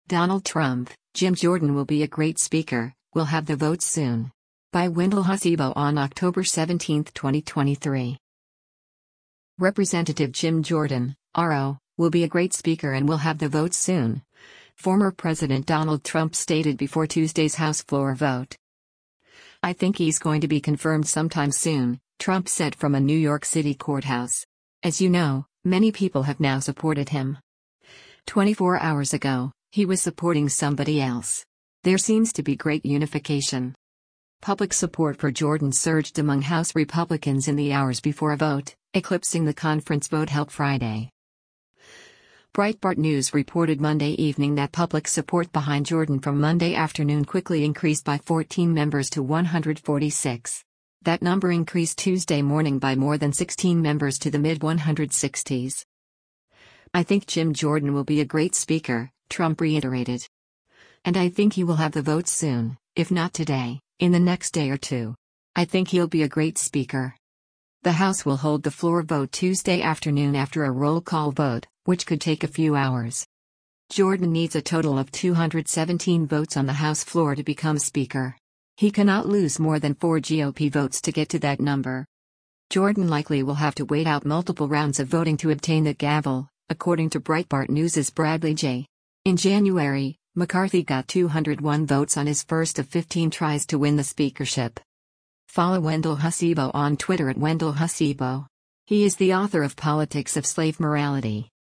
“I think he’s going to be confirmed sometime soon,” Trump said from a New York City courthouse.